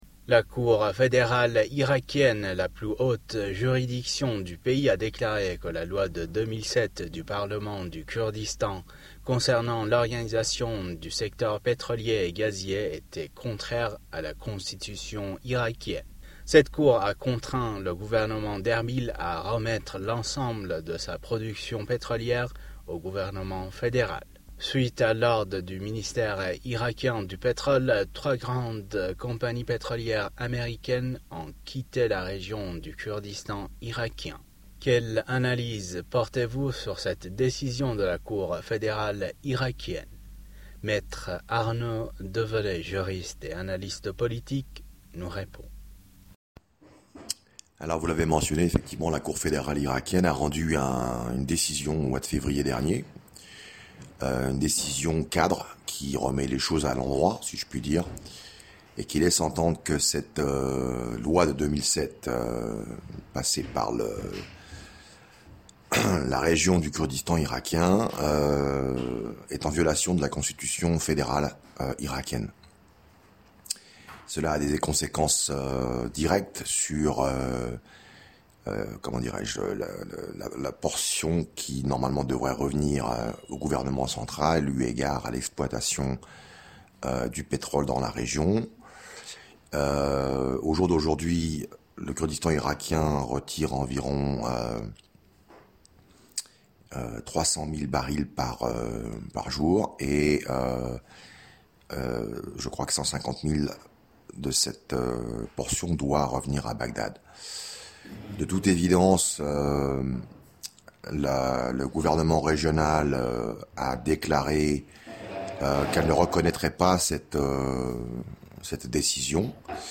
juriste et analyste politique s'exprime sur le sujet.